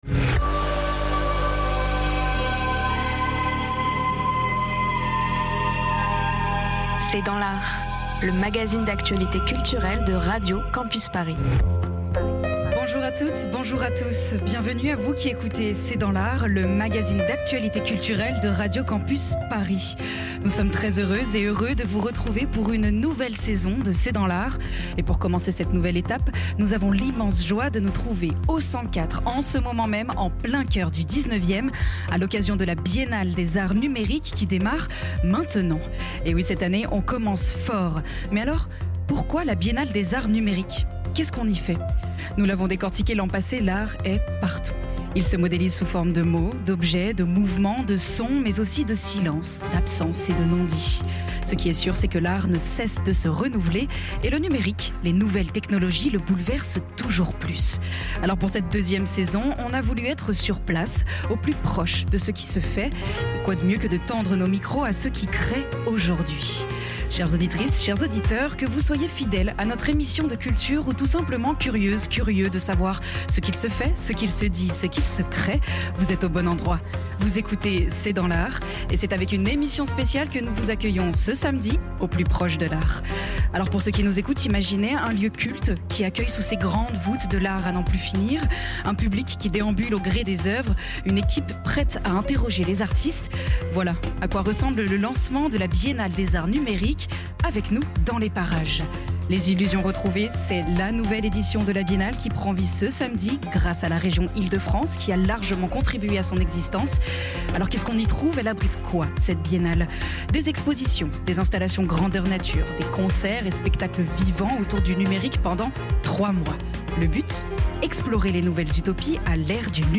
L’équipe de C’est dans l’art est à l’inauguration de la biennale des arts numériques de la région Ile-de-France au Centquatre